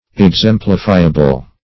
Meaning of exemplifiable. exemplifiable synonyms, pronunciation, spelling and more from Free Dictionary.
Exemplifiable \Ex*em"pli*fi`a*ble\, a. That can be exemplified.